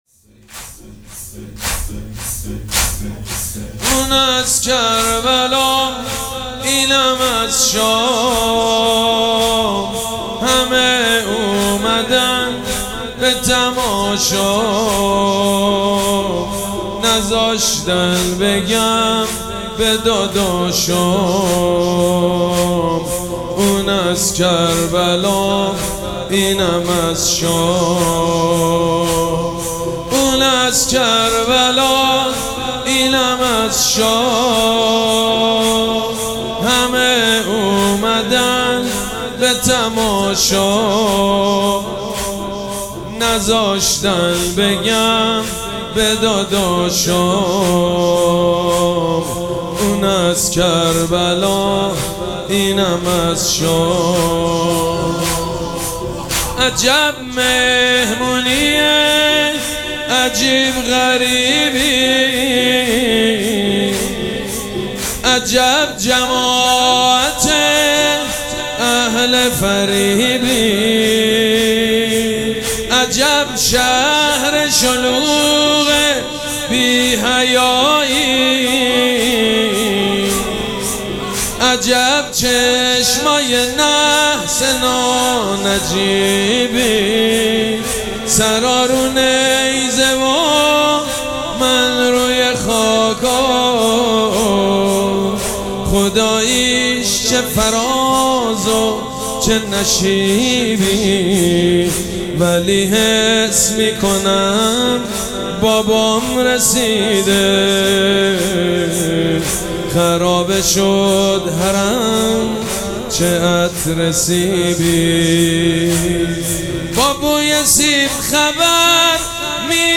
مراسم عزاداری شب سوم محرم الحرام ۱۴۴۷
مداح
حاج سید مجید بنی فاطمه